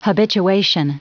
Prononciation du mot habituation en anglais (fichier audio)
Prononciation du mot : habituation